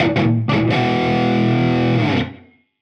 AM_HeroGuitar_85-E01.wav